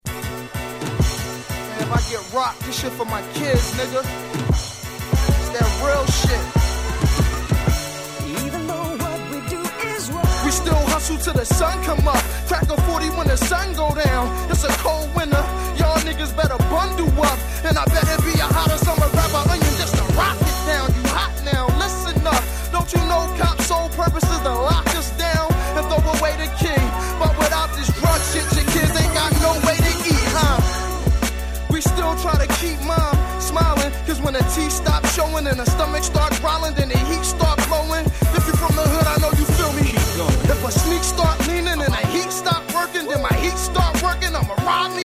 ※試聴ファイルは別の盤から録音してあります。
02' Smash Hit Hip Hop !!